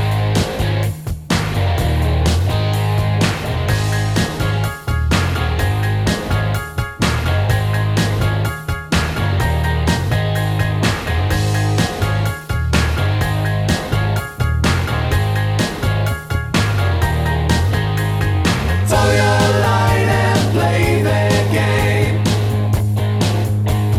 One Semitone Down Rock 3:40 Buy £1.50